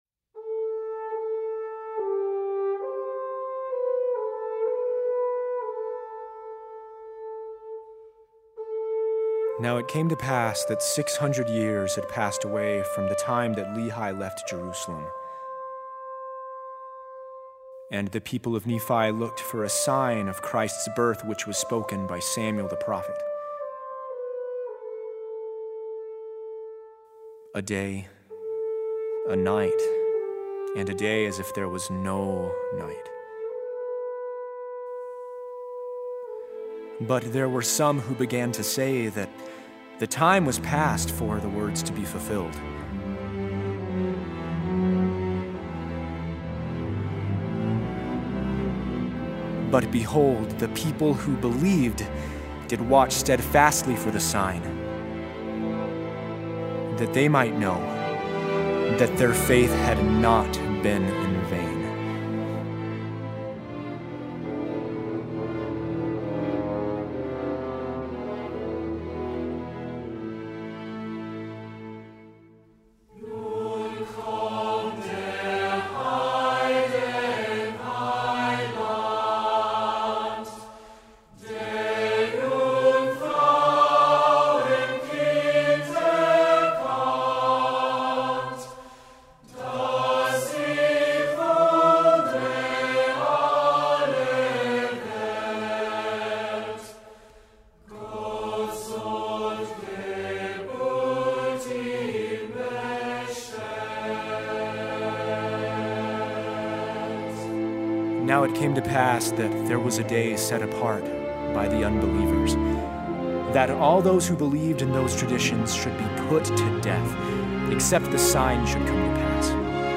Baritone, Horn, SATB, and Full Orchestra.